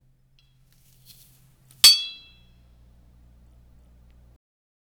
swords clash
swords-clash-qnoxlllz.wav